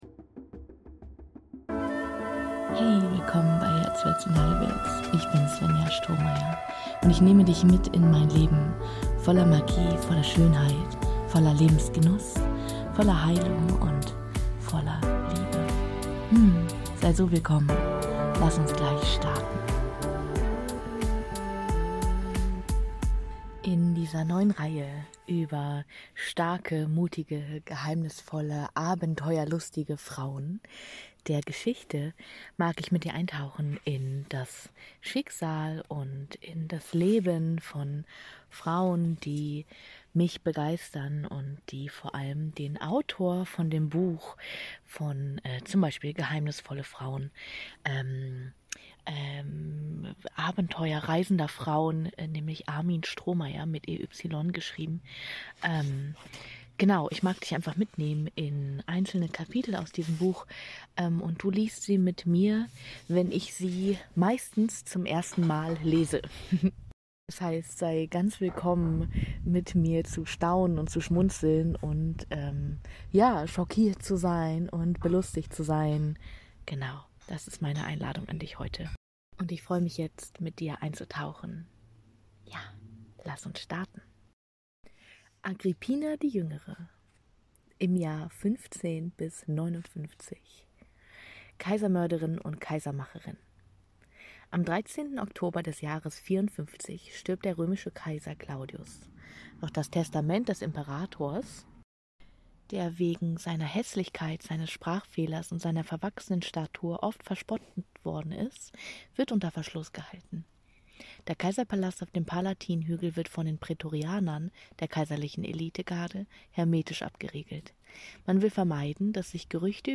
Sei willkommen, mit mir zu staunen, zu schmunzeln, schockiert und belustigt zu sein, wenn ich die Kapitel mit dir lese! Heute starten wir mit der Kaisermörderin und Kaisermacherin Agrippina!